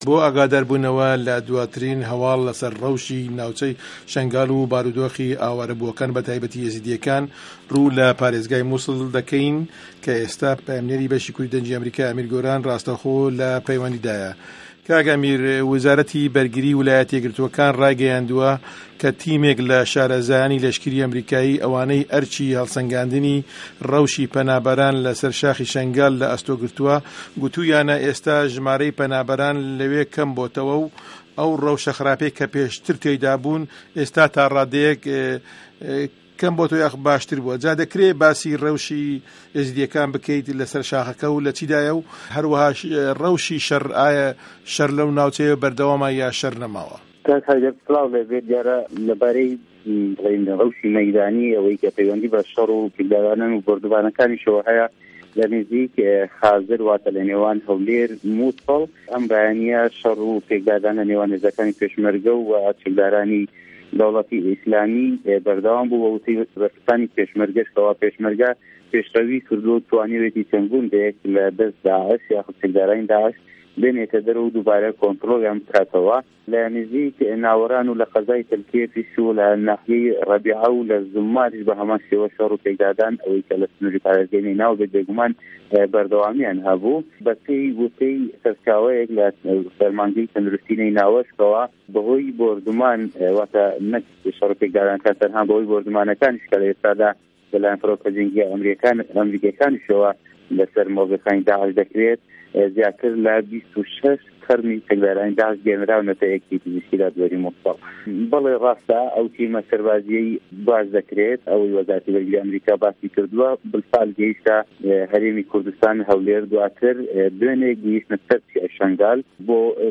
ڕاسته‌وخۆ له‌ به‌رنامه‌کانی ڕادیۆ ڕاپـۆرتێـکی پـێشکه‌شکرد